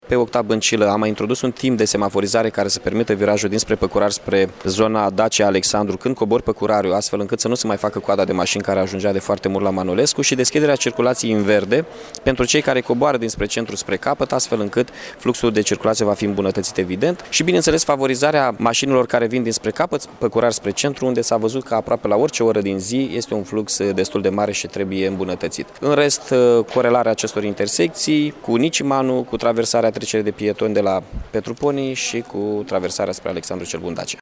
Primarul interimar al Iașului, Mihai Chirica: